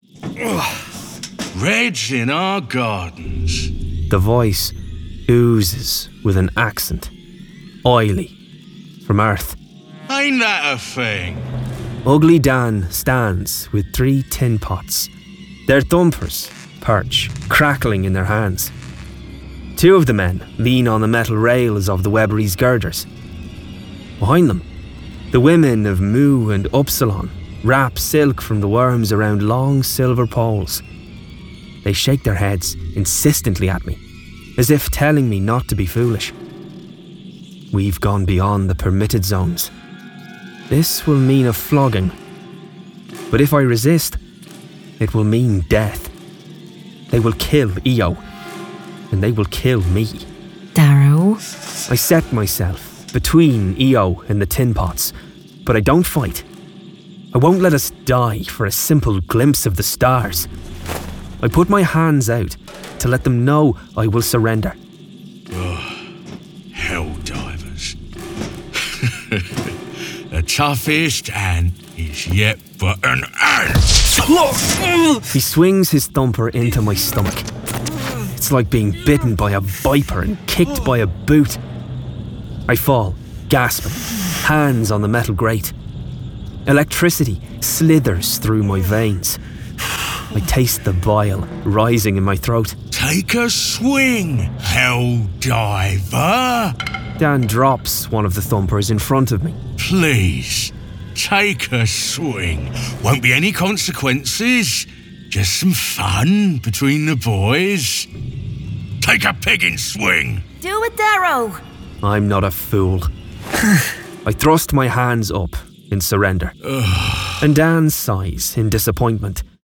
Full Cast. Cinematic Music. Sound Effects.
Genre: Science Fiction
Adapted from the novel and produced with a full cast of actors, immersive sound effects and cinematic music!